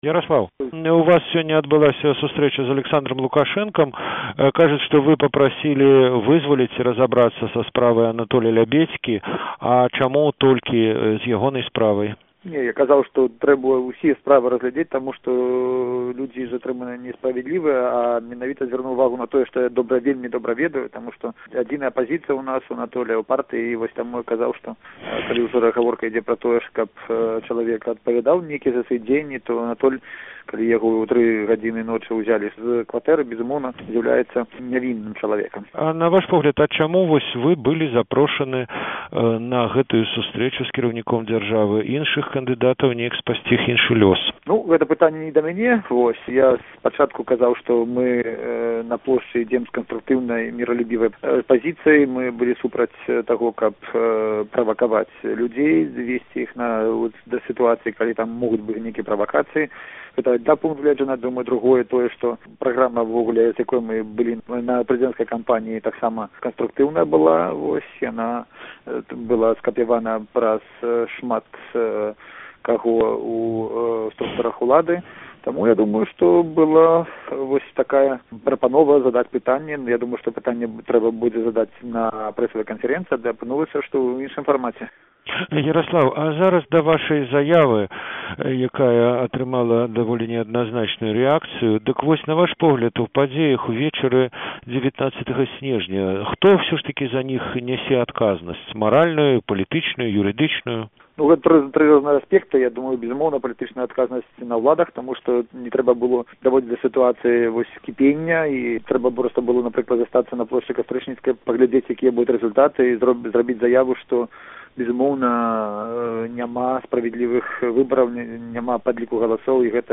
Інтэрвію Яраслава Раманчука. 20.12.2010